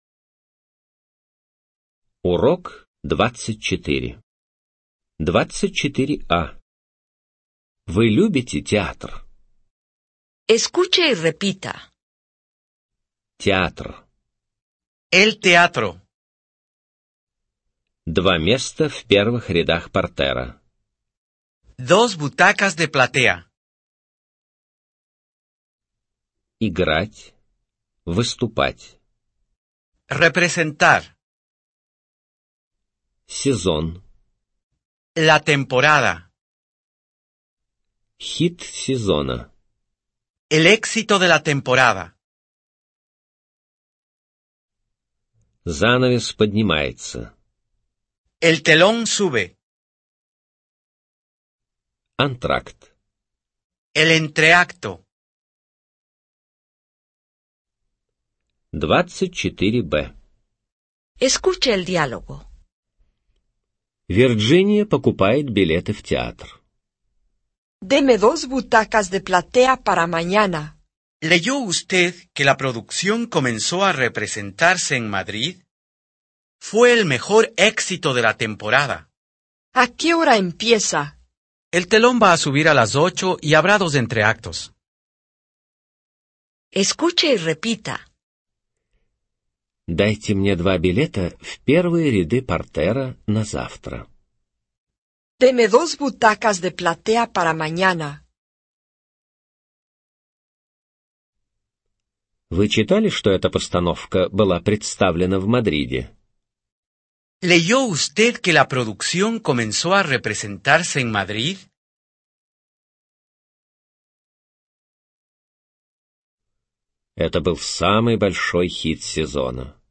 Аудио разговорник испанского языка — часть 25